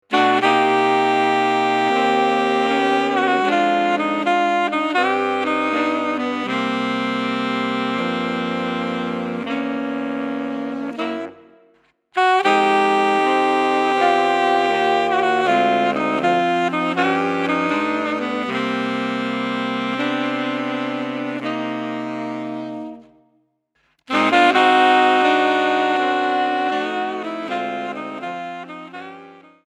4 Saxophones (2ATB/2A2TB)